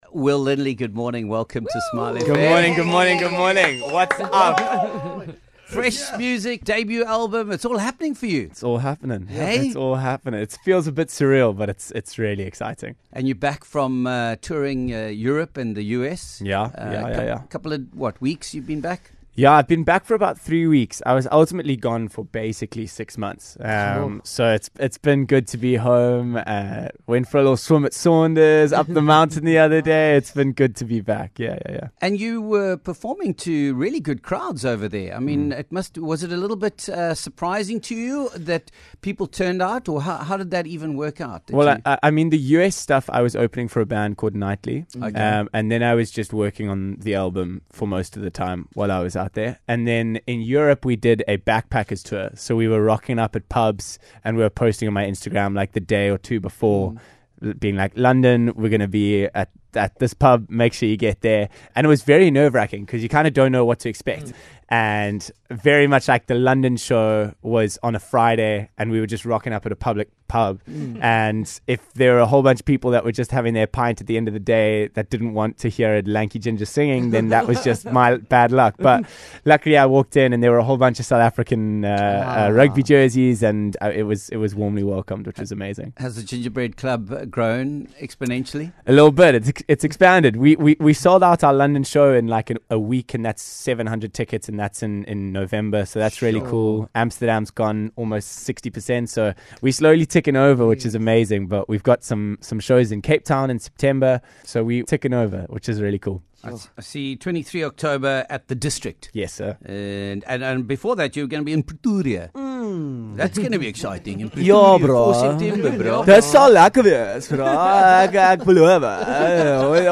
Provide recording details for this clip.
a magical live performance right in studio